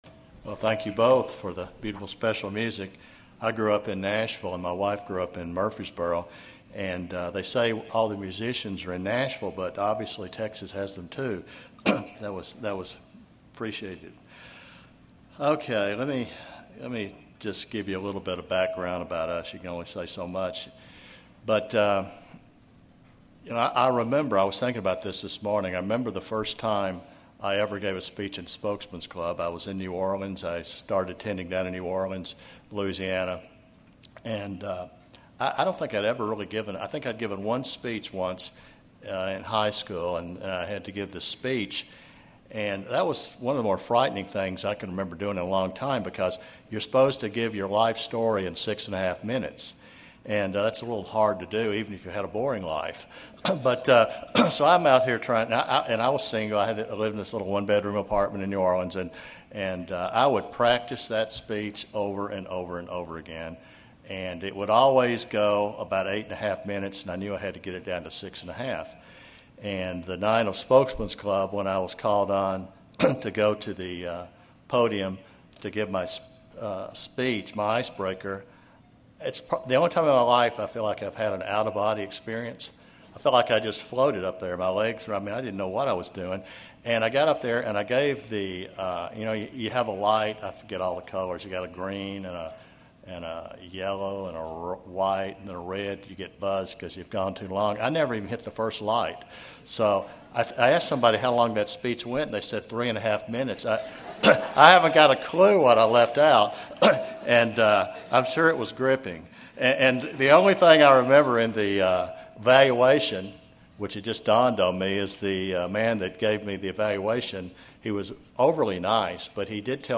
If you are like me and tend to complain a bit too much at times, then this sermon hopefully will help you be more appreciative.
Given in Dallas, TX